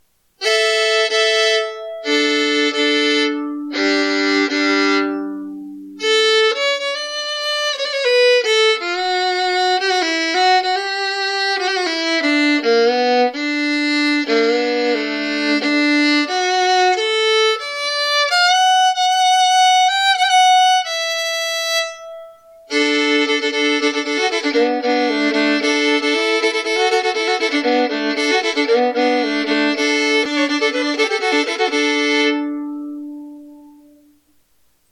Vintage American Lindsey Violin/Fiddle $2100